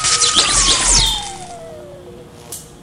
beambroken.ogg